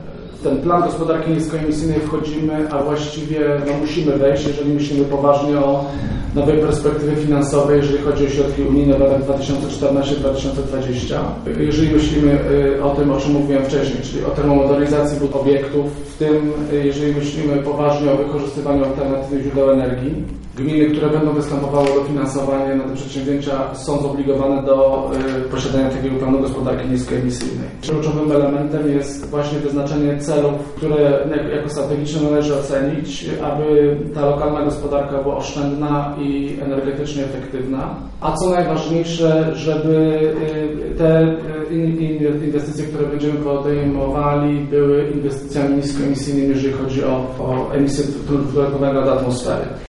mówił burmistrz Żnina Robert Luchowski.